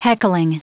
Prononciation du mot heckling en anglais (fichier audio)
Prononciation du mot : heckling